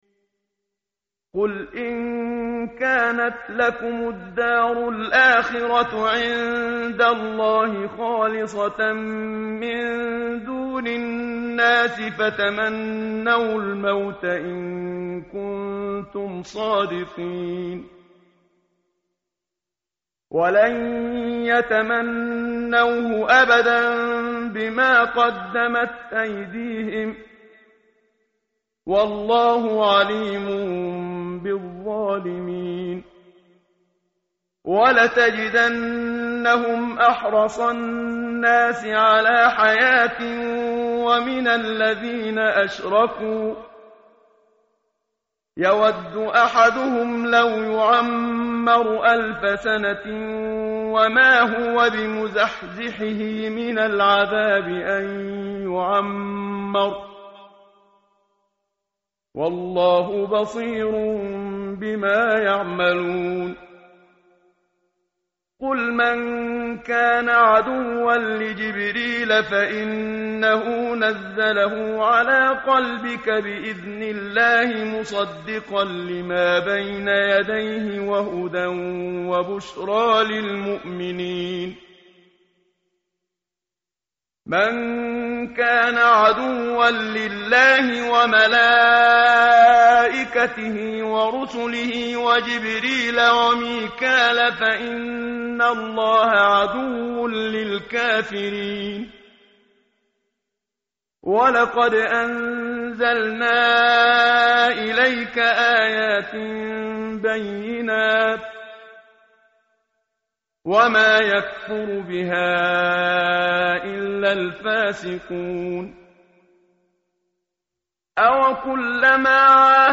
متن قرآن همراه باتلاوت قرآن و ترجمه
tartil_menshavi_page_015.mp3